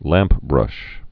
(lămpbrŭsh)